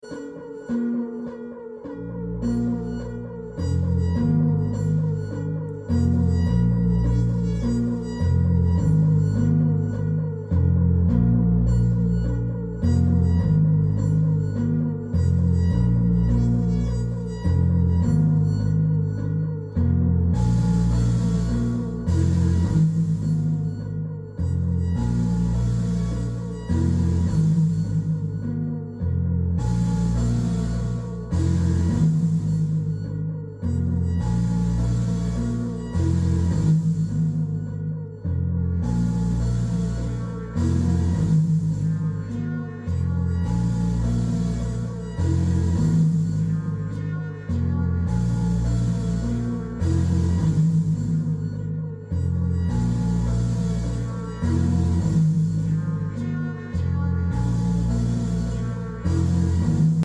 👉 Moody multi-part sequence on the Maximus 8V. The Maximus synthesizer is much more than a polysynth -- it is an 8-voice analog workstation, where each synth voice is a versatile, robust synth in itself, capable of a multitude of synth and percussion sounds.